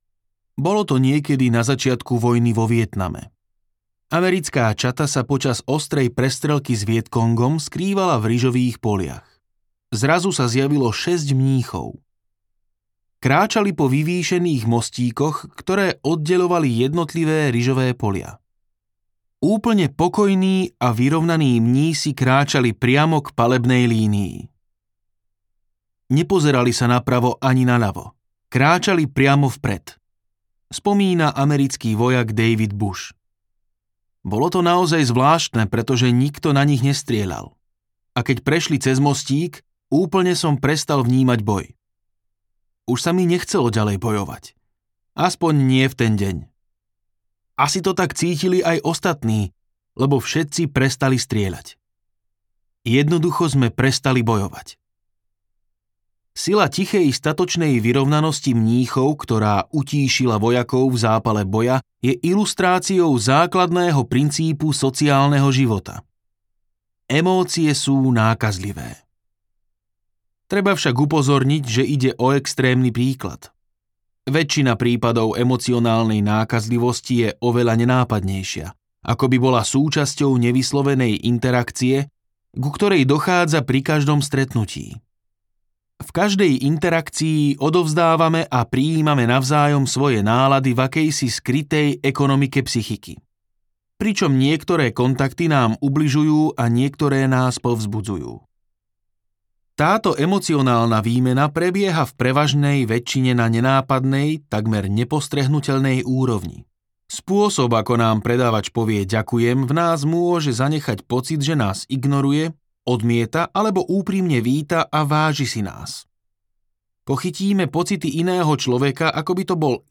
Audiokniha Emocionálna inteligencia - Daniel Goleman | ProgresGuru